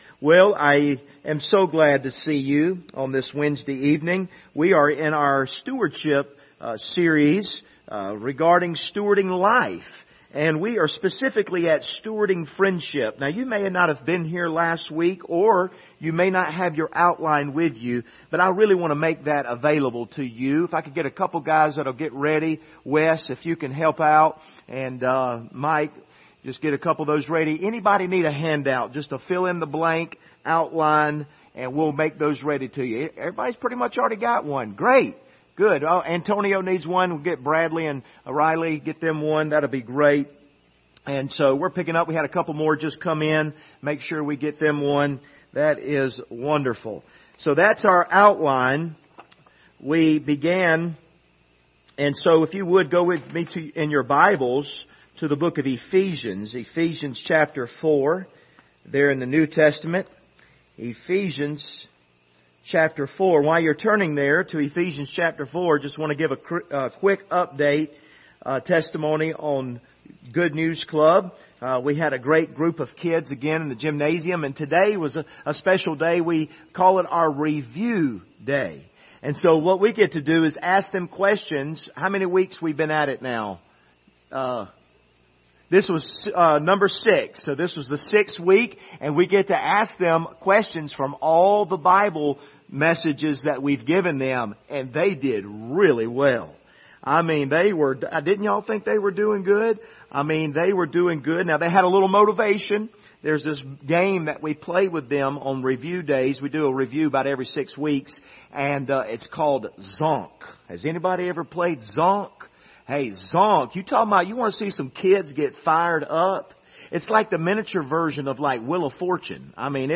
Passage: Ephesians 4:25-32 Service Type: Wednesday Evening